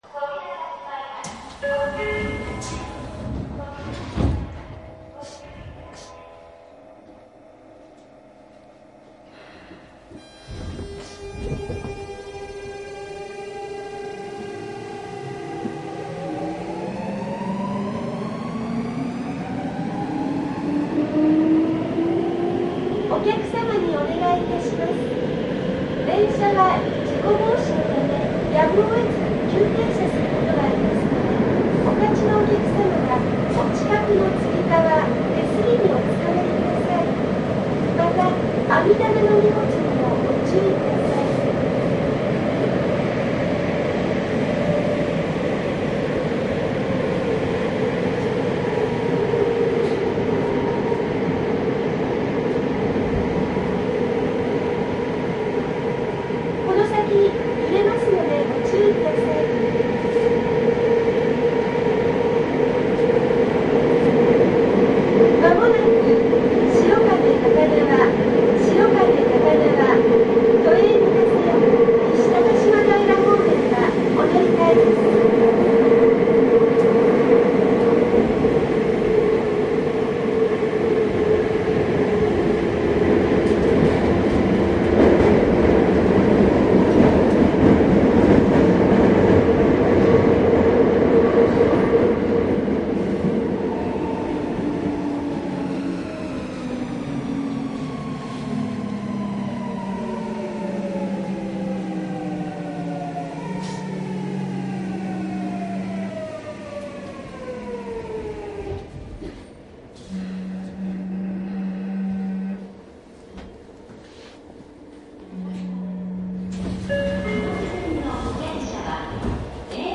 主に9000系のうちGTOタイプで日立製VVVF制御の編成を収録（この音は東急2000系と同じタイプ）。
マスター音源はデジタル44.1kHz16ビット（マイクＥＣＭ959）で、これを編集ソフトでＣＤに焼いたものです。